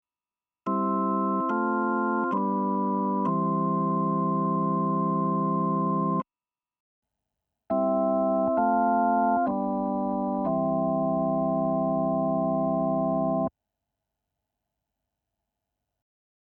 Dazu folgende Anmerkungen: Bis auf Hörbeispiel 14 ist immer zunächst der Nord Electro 5D zu hören, dann das Yamaha YC61 Stage Keyboard.